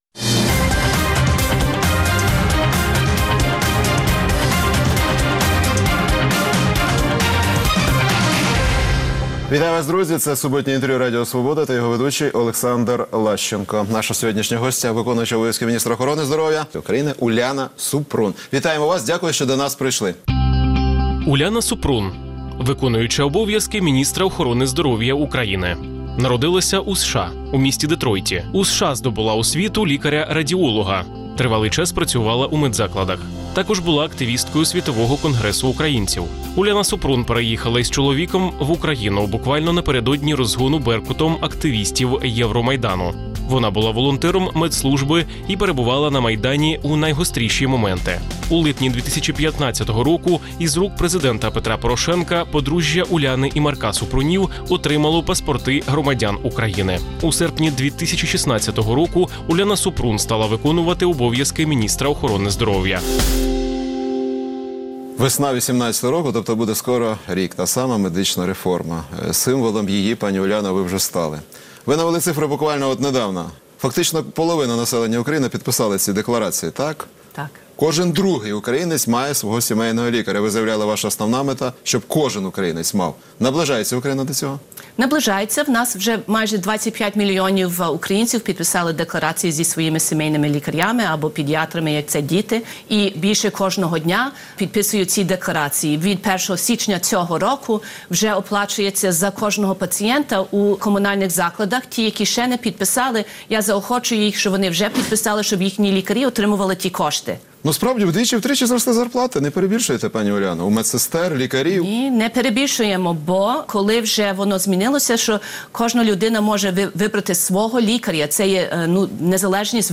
Суботнє інтерв’ю | Уляна Супрун, виконувачка обов’язків міністра охорони здоров’я
Суботнє інтвер’ю - розмова про актуальні проблеми тижня. Гість відповідає, в першу чергу, на запитання друзів Радіо Свобода у Фейсбуці